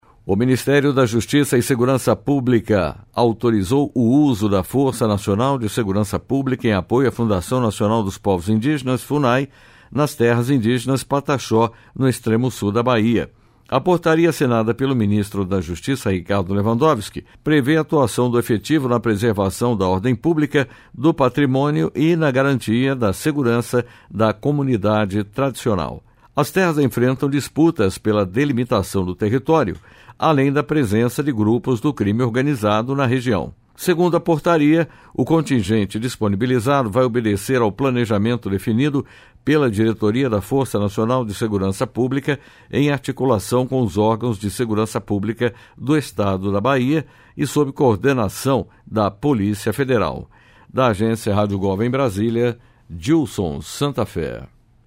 Operação Sem Desconto expõe fraude a aposentados em coletiva de imprensa
Em entrevista coletiva sobre a operação, com a participação do ministro da Justiça e Segurança Pública, Ricardo Lewandowski, e do ministro da Previdência Social, Carlos Lupi, foi informado que 700 policiais federais e 80 servidores da CGU cumprem 211 mandados de busca e apreensão e seis de prisão temporária.